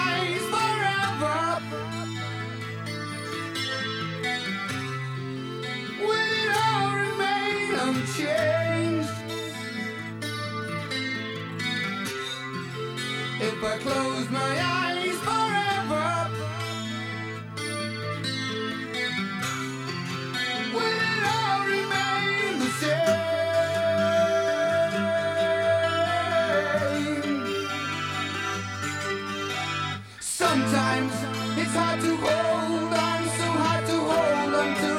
Hard Rock
Hair Metal
Жанр: Рок / Метал